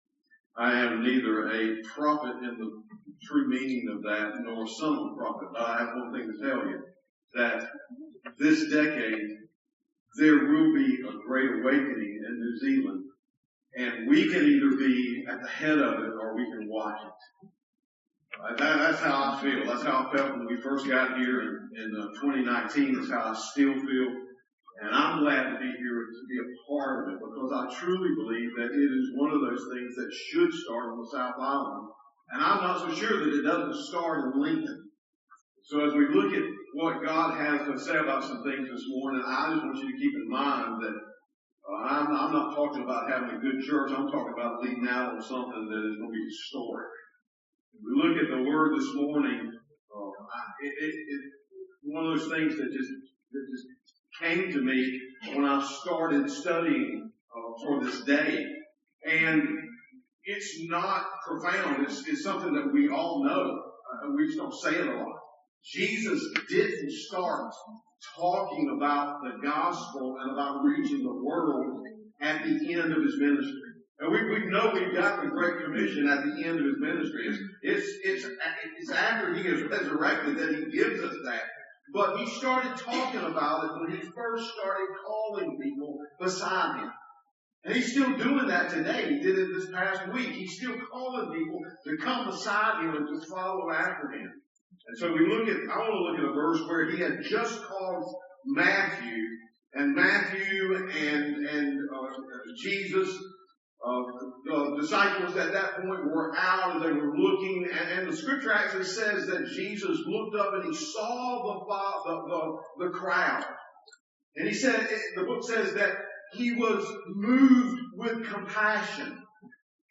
Sermons | Lincoln Baptist Church